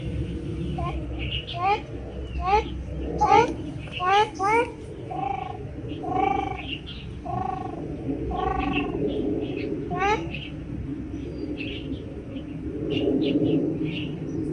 赤麻鸭叫声